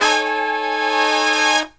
LONG HIT05-L.wav